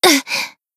BA_V_Utaha_Cheerleader_Battle_Damage_1.ogg